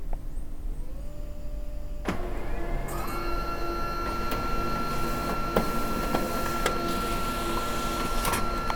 製品の稼動中に以下のファイルの音が発生しますが、正常な稼動音です。
・印刷中